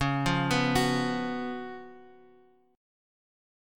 Listen to Db11 strummed